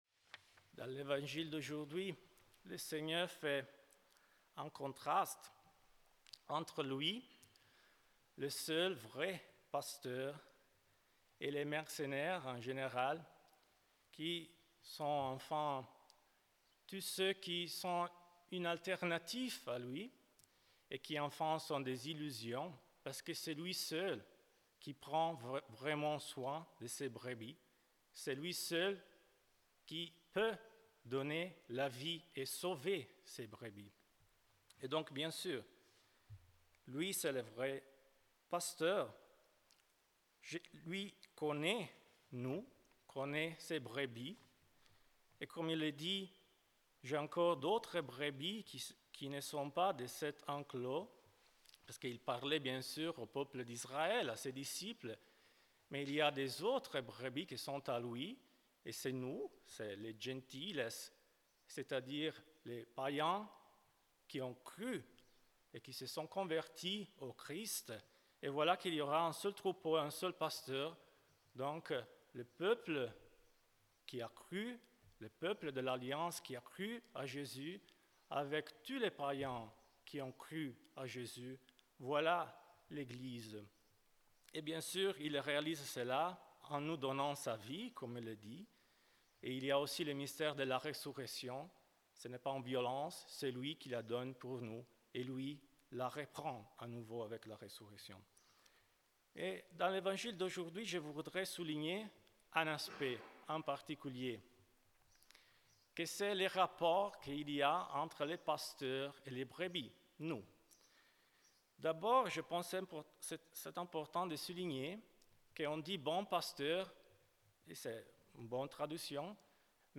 Ce matin dans la chapelle du couvent St-Hyacinthe